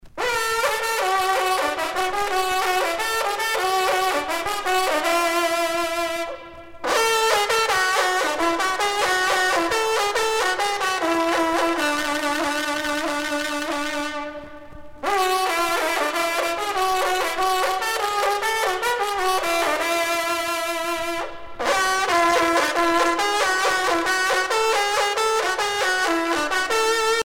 trompe - fanfare
circonstance : vénerie
Pièce musicale éditée